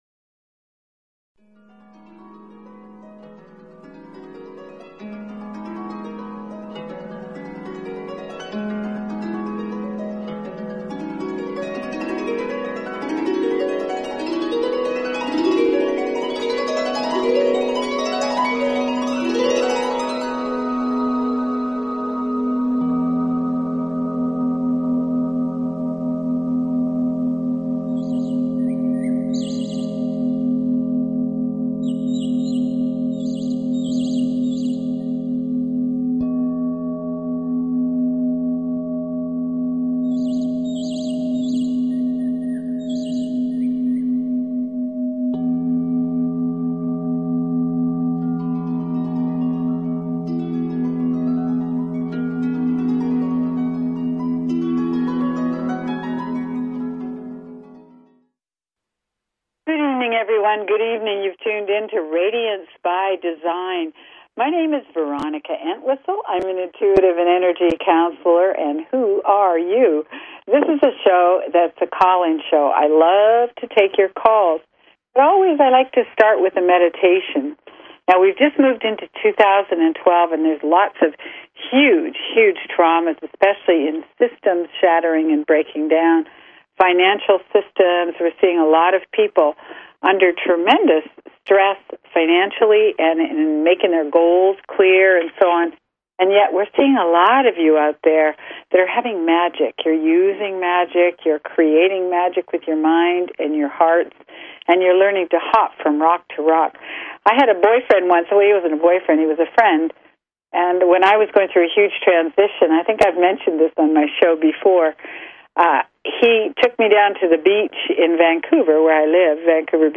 Talk Show Episode, Audio Podcast, Radiance_by_Design and Courtesy of BBS Radio on , show guests , about , categorized as
Show Headline Radiance_by_Design Show Sub Headline Courtesy of BBS Radio Radiance by Design - January 5, 2012 Radiance By Design Please consider subscribing to this talk show.